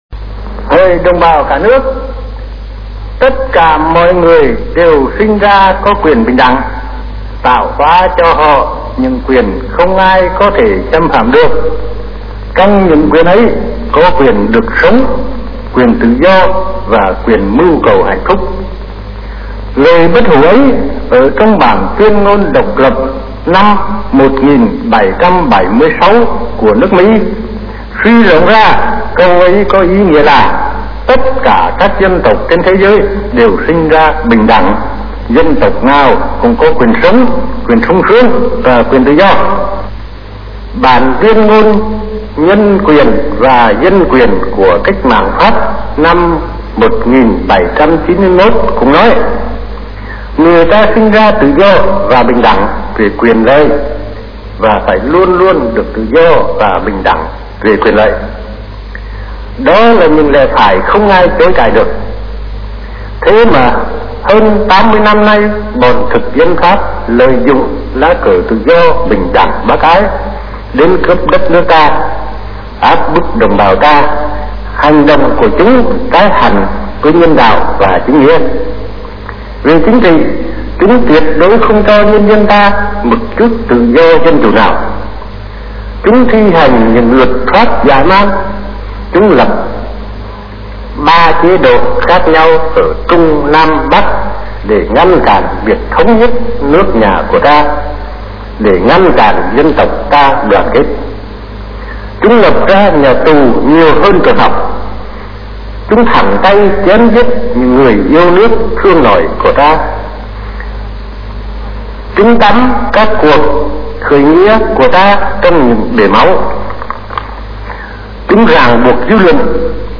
Bản tuyên ngôn độc lập của Việt Nam, sau khi giành độc lập từ thực dân PhápNhật, đã được Hồ Chí Minh soạn thảo, và đọc trước công chúng tại vườn hoa Ba Đình (nay là quảng trường Ba Đình) ngày 2 tháng 9 năm 1945.
Ngày 2 tháng 9 năm 1945, Hồ Chí Minh đọc bản Tuyên ngôn độc lập trong cuộc mít tinh trước hàng chục vạn đồng bào Thủ đô, tại vườn hoa Ba Đình, khai sinh nước Việt Nam Dân chủ Cộng hòa.